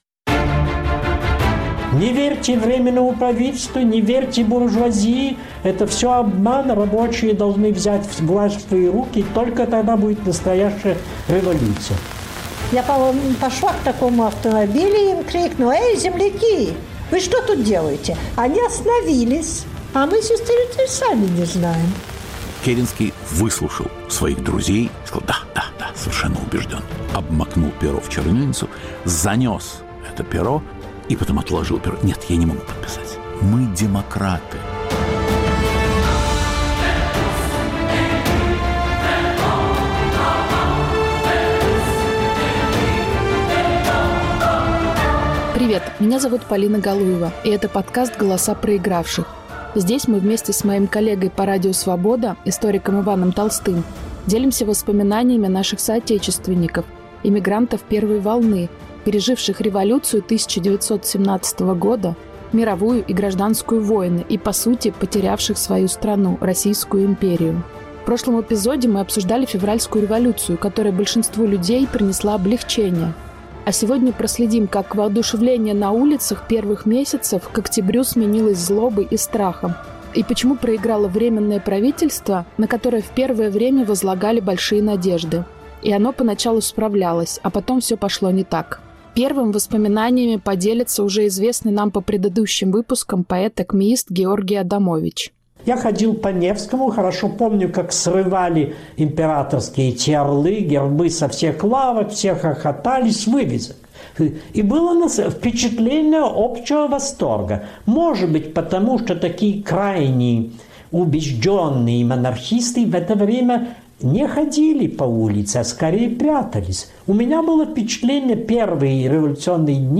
О кануне Октября - голосами очевидцев